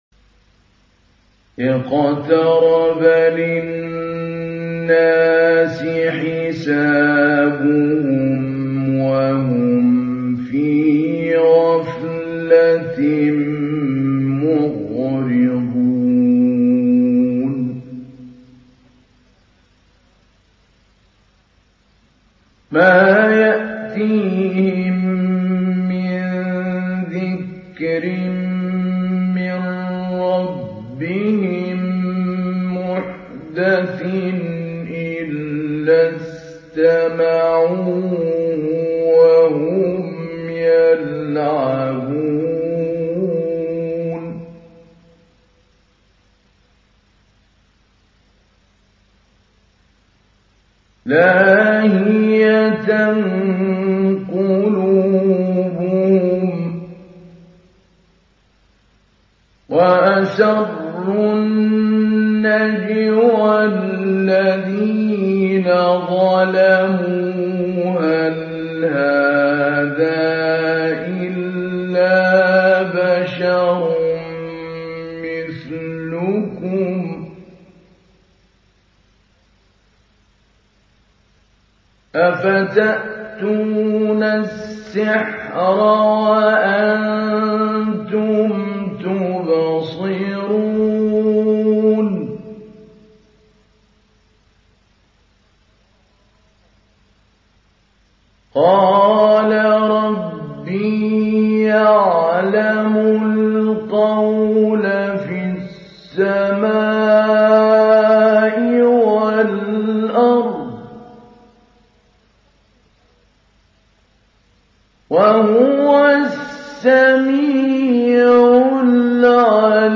Download Surah Al Anbiya Mahmoud Ali Albanna Mujawwad